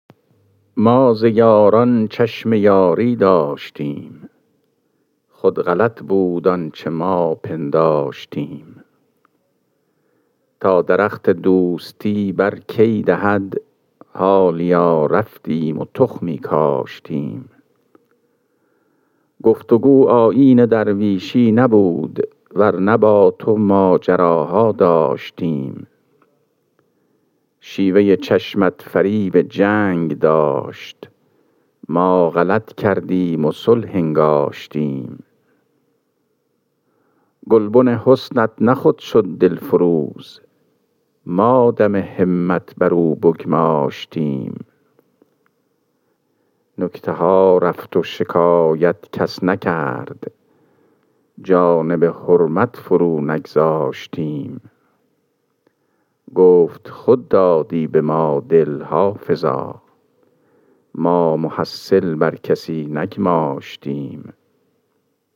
خوانش غزل شماره ۳۶۹ دیوان حافظ